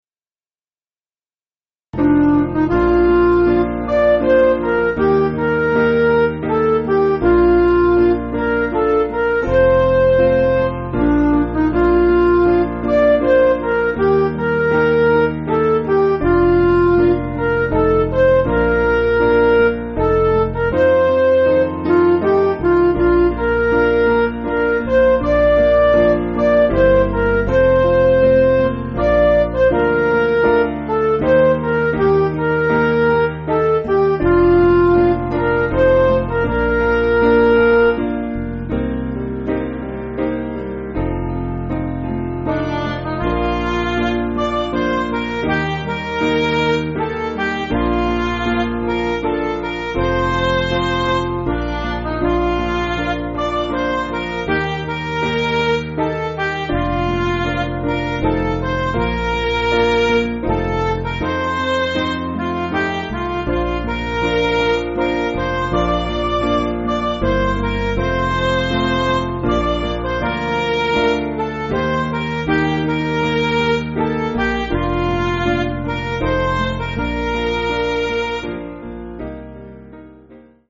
Piano & Instrumental
(CM)   5/Bb